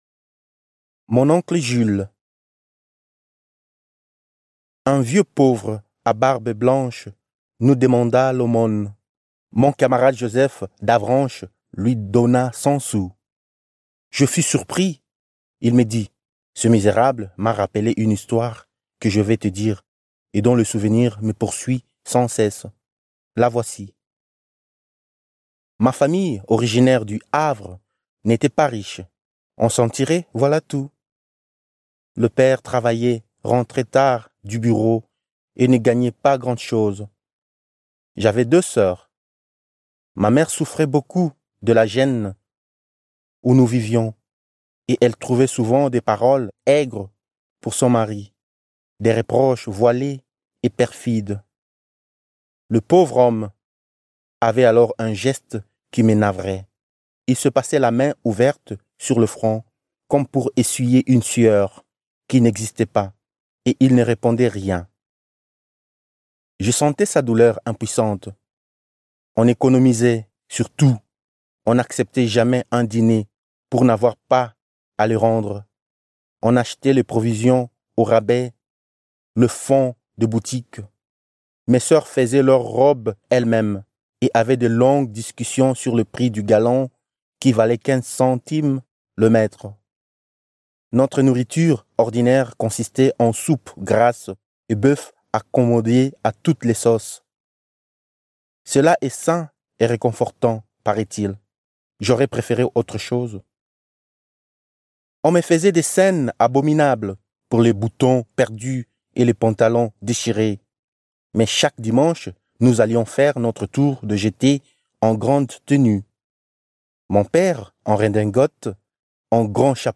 Un livre audio enregistré par un locuteur natif est une occasion unique non seulement de se familiariser directement avec le travail, mais aussi de plonger dans l'aura de la langue: phonétique, intonation, rythme de la parole. Ce livre audio comprend des nouvelles de Guy de Maupassant, connues dans la traduction russe sous les noms de «Collier», «Vendetta» et «Oncle Jules».